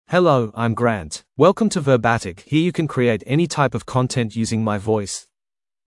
Grant — Male English (Australia) AI Voice | TTS, Voice Cloning & Video | Verbatik AI
Grant is a male AI voice for English (Australia).
Voice sample
Grant delivers clear pronunciation with authentic Australia English intonation, making your content sound professionally produced.
Grant's clear male voice ensures learners in Australia English can follow along easily, improving comprehension and retention.